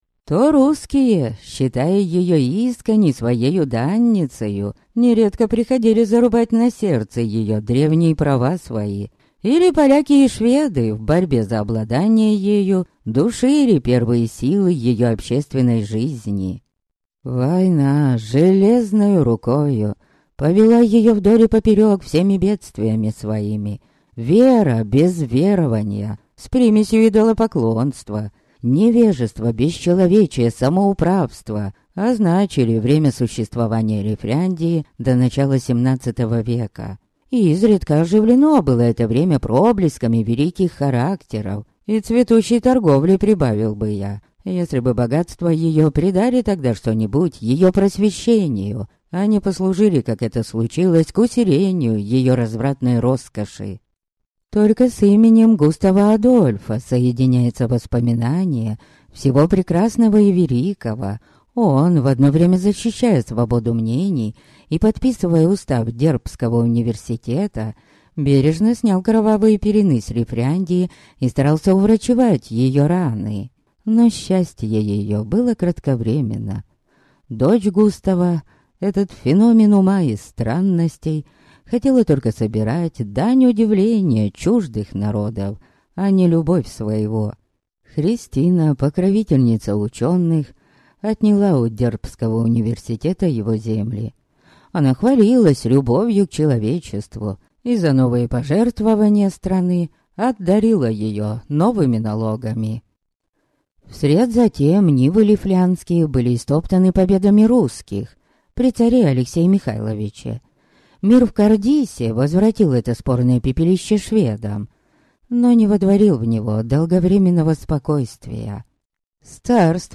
Аудиокнига Последний Новик. Том 1 | Библиотека аудиокниг
Прослушать и бесплатно скачать фрагмент аудиокниги